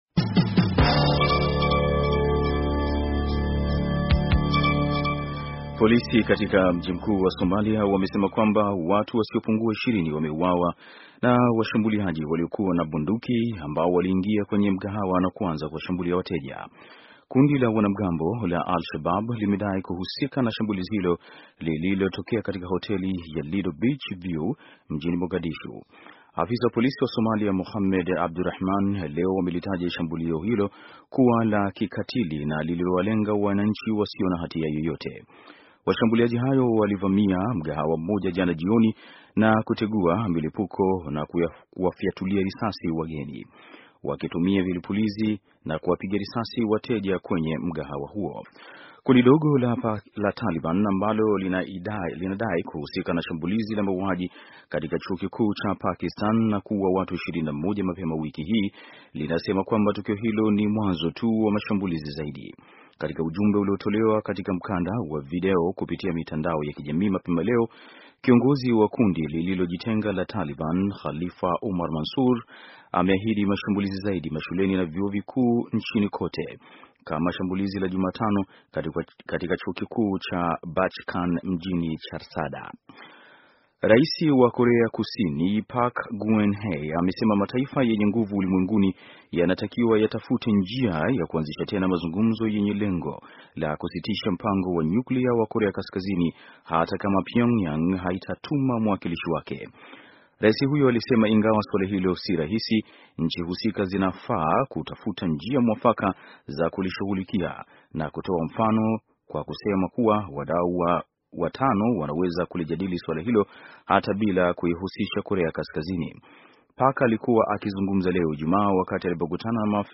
Taarifa ya habari - 5:44